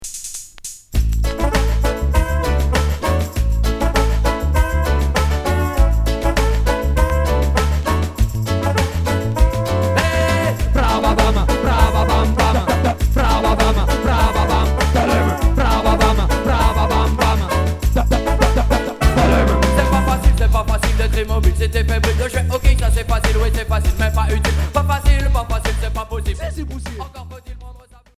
Reggae rub-a-dub Unique 45t retour à l'accueil